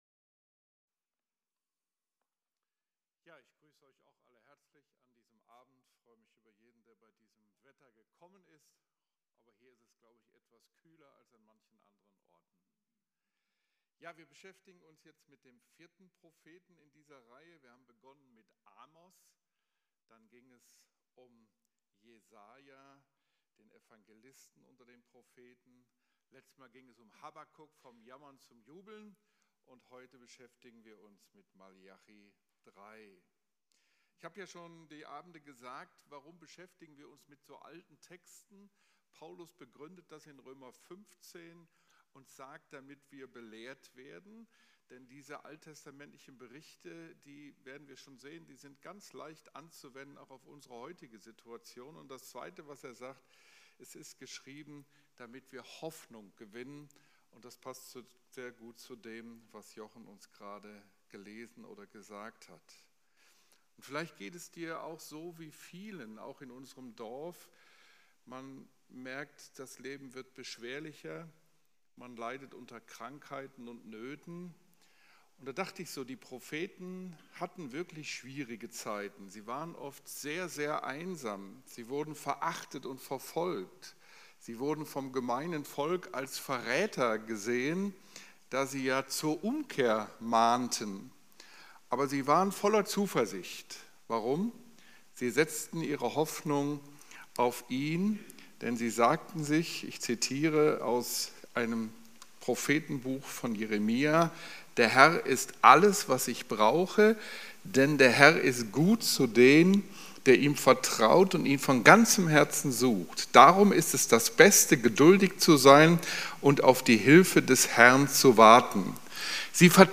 13.08.2025 Themenabend #3 ~ Predigten - FeG Steinbach Podcast